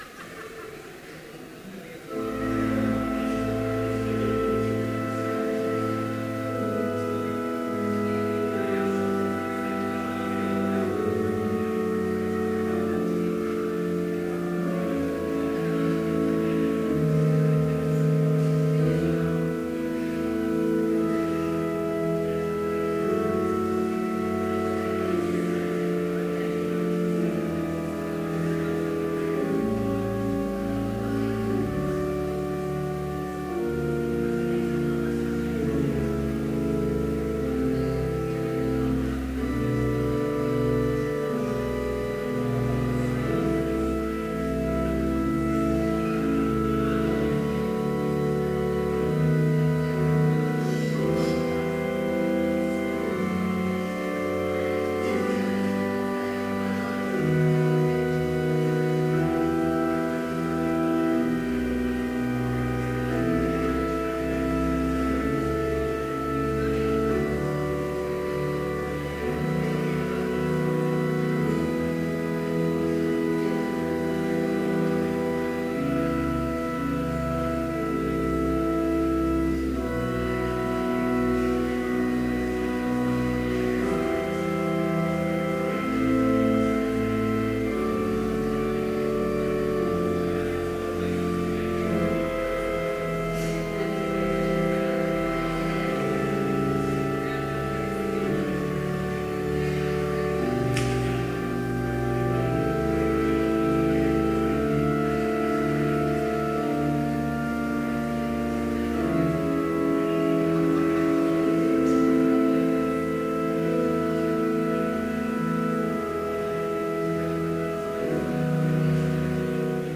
Complete service audio for Chapel - September 5, 2014